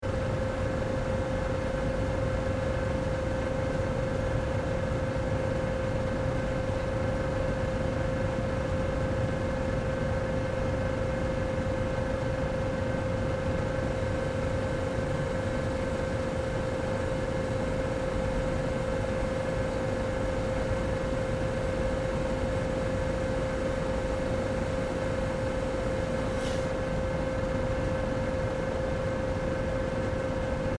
Stock Preparation Plant
The CPMP bales move to the pulper to be grinded to mass with water.
• cardboard machines
• cardboard mill